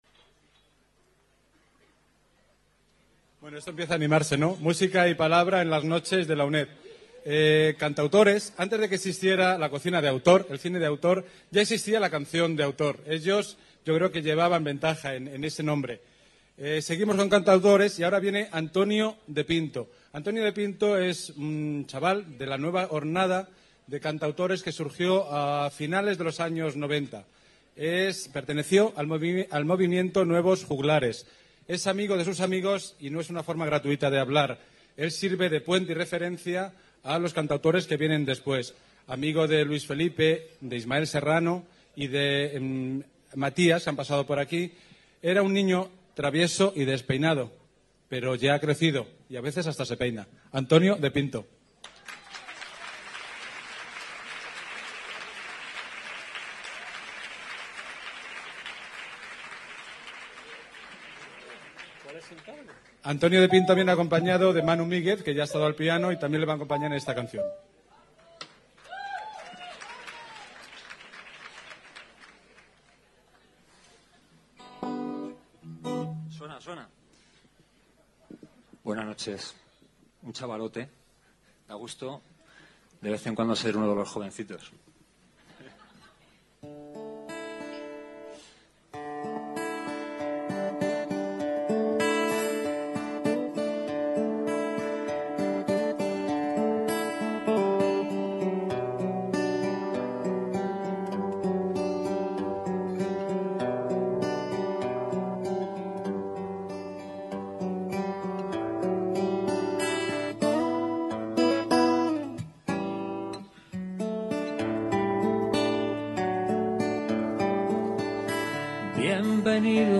Noche de Canción de autor